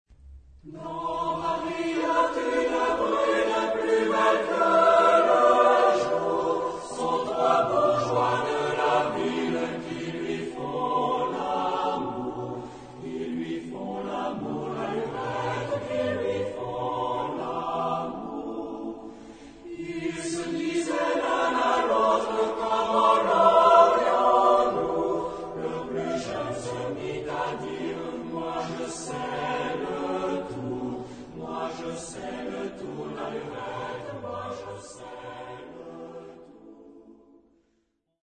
Genre-Style-Forme : Profane ; Populaire
Type de choeur : SATB  (4 voix mixtes )
Solistes : Soprano (1) / Mezzo (1) / Ténor (1)  (2 soliste(s))
Tonalité : mi mode de ré
Origine : Canada ; Québec